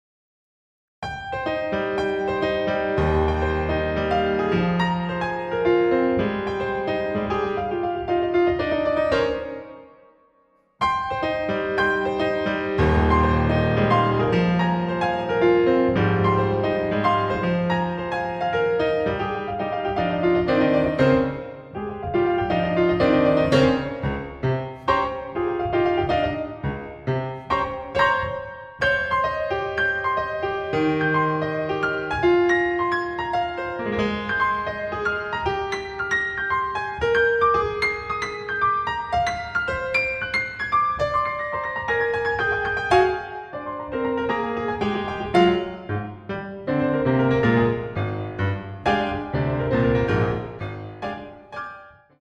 • Genres: Solo Piano, Classical